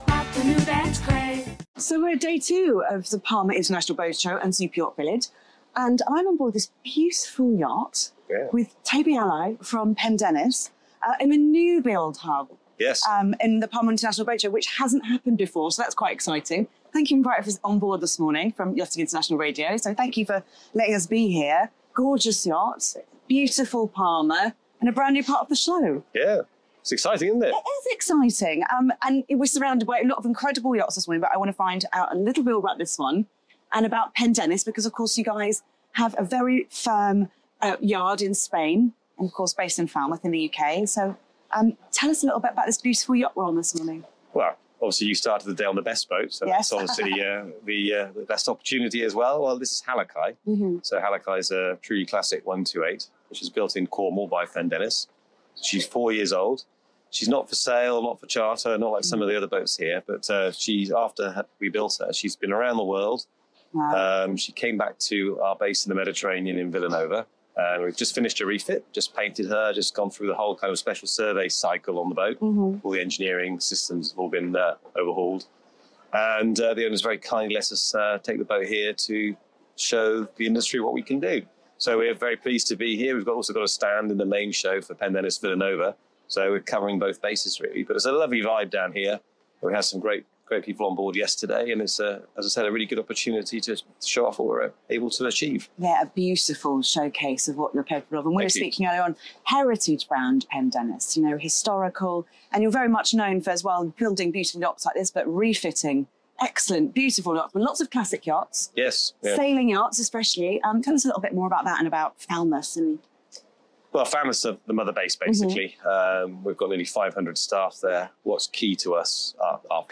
📍 Recorded at the Palma Superyacht Village 2025